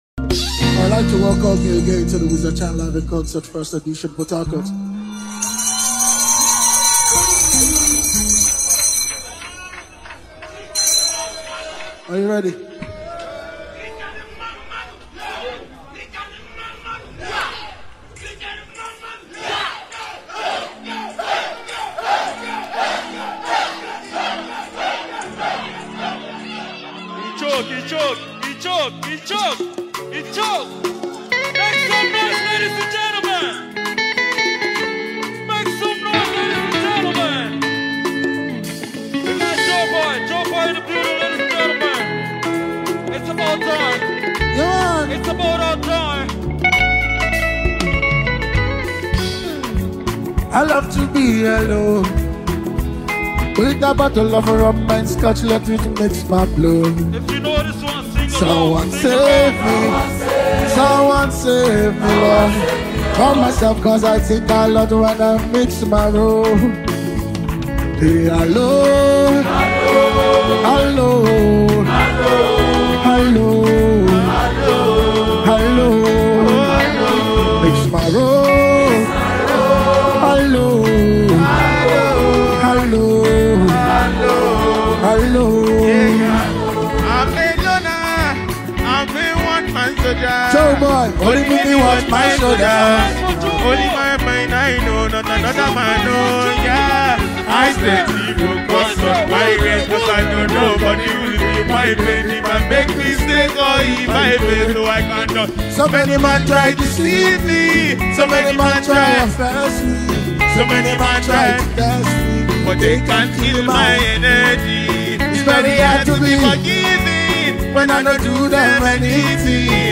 Prominent Nigerian Singer